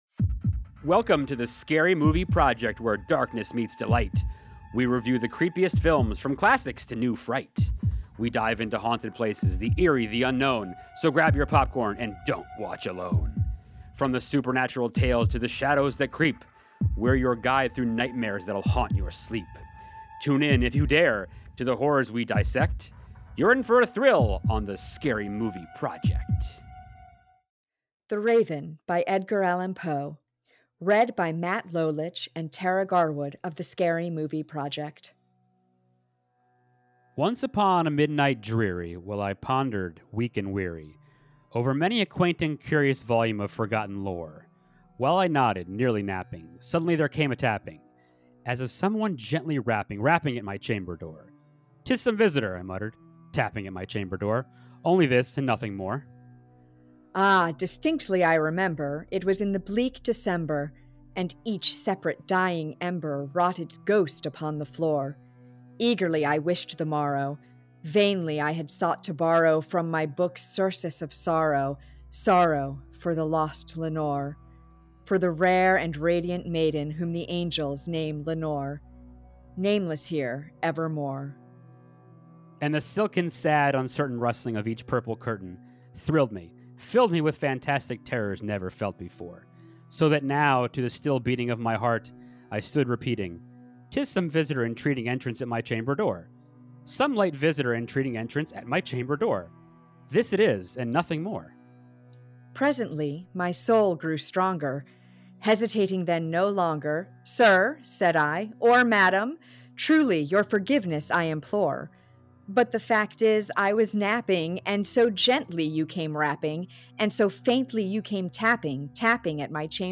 the-raven-reading.mp3